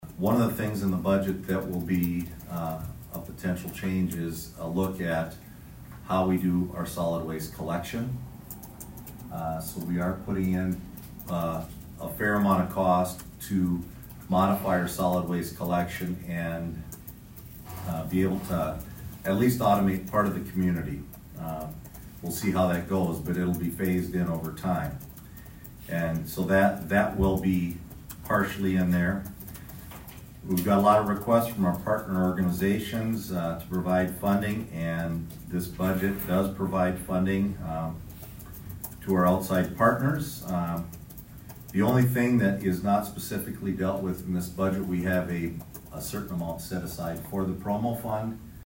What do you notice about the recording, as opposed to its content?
ABERDEEN, S.D.(HubCityRadio)- On Monday night, the Aberdeen City Council did a work session to address the budget for the year 2026.